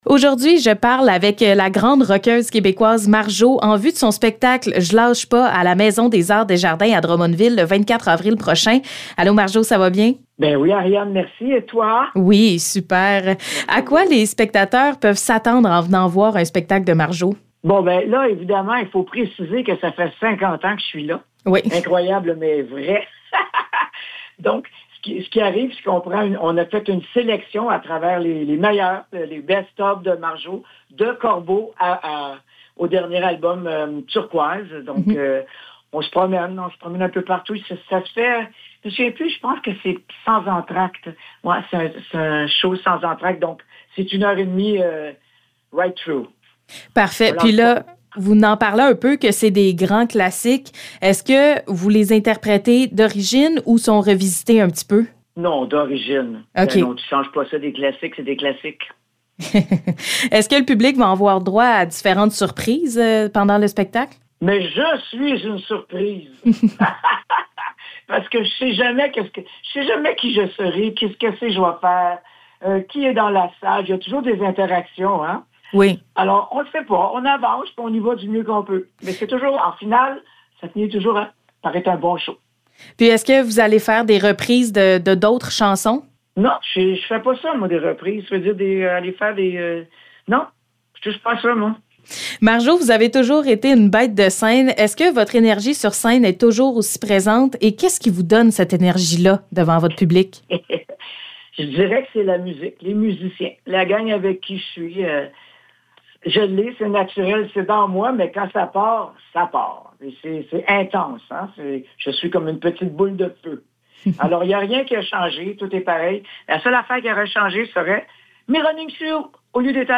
Entrevue avec Marjo